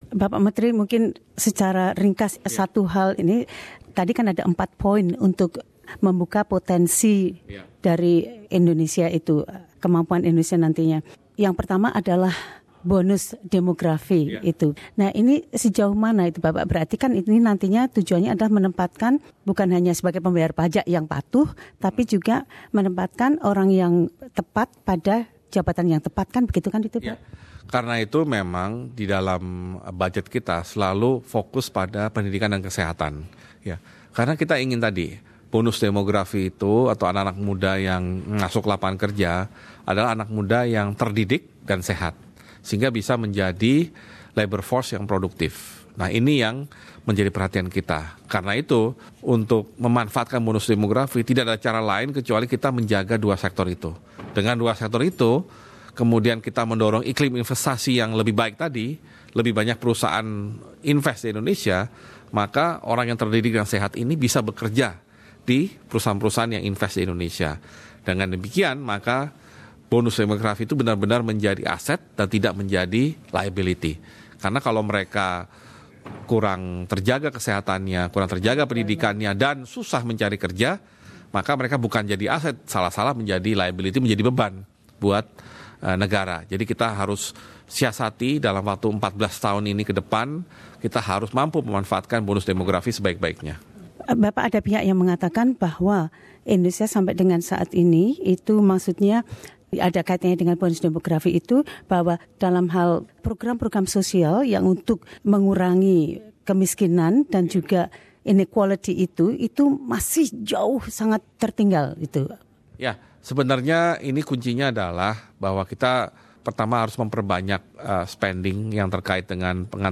Prof Dr Bambang Brodjonegoro giving a public lecture at Melbourne University – 15 Aug 2016.